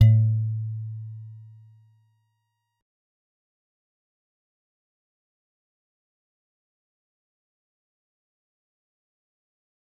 G_Musicbox-A2-mf.wav